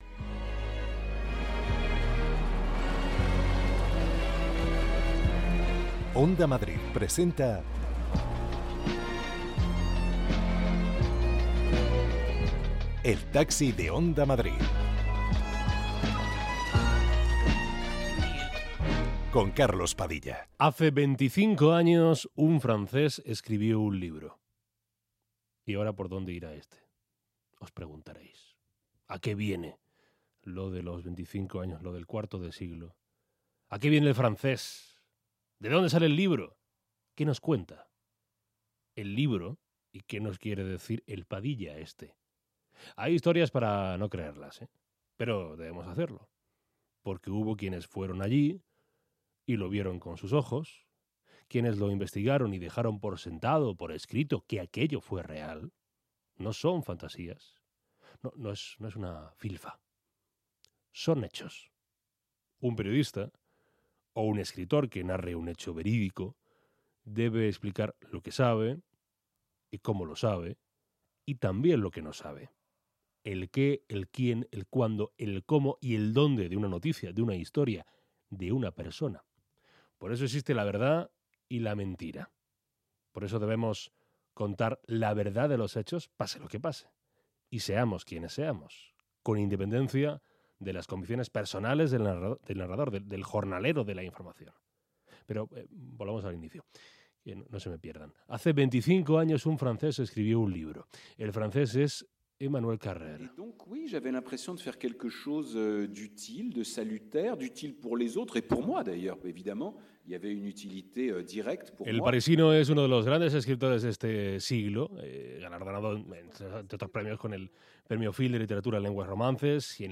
Conversaciones para escapar del ruido. Recorremos Madrid con los viajeros más diversos del mundo cultural, político, social, periodístico de España...